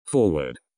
Tags: voice control robot